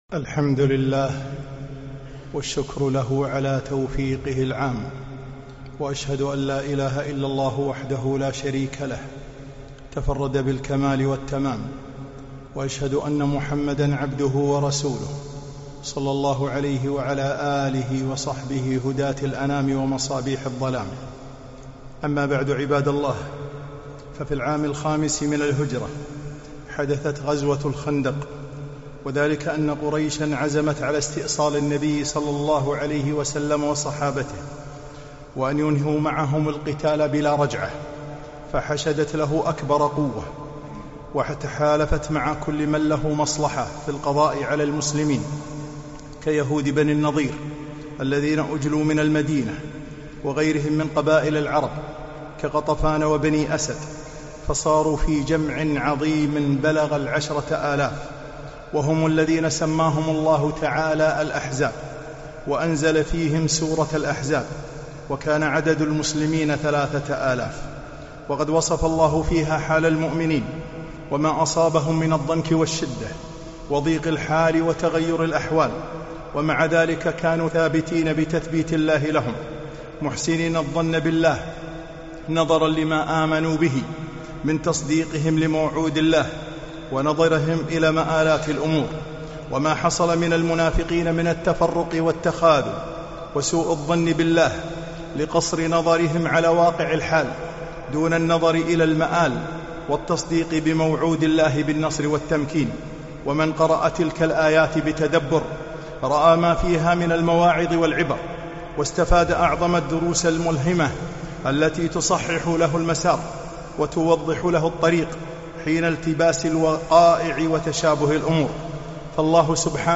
خطبة - دروس من سورة الأحزاب